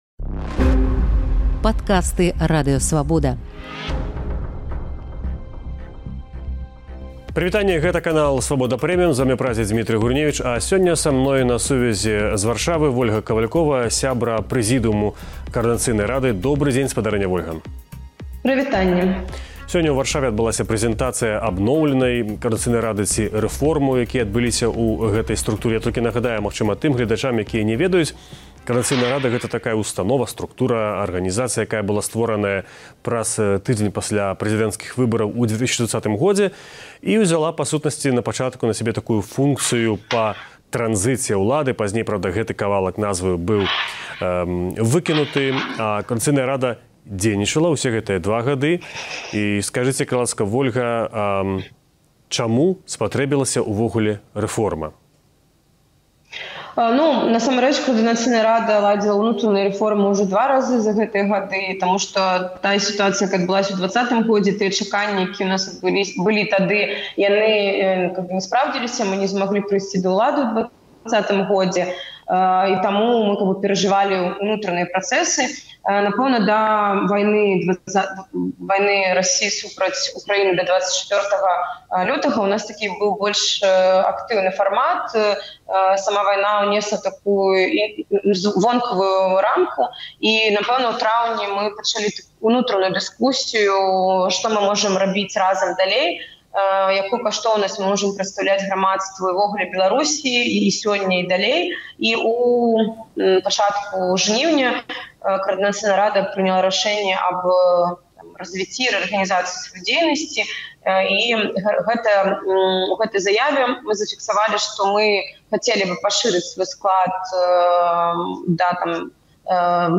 Пра гэта ў інтэрвію Свабодзе расказала прадстаўніца прэзыдыюму КР Вольга Кавалькова.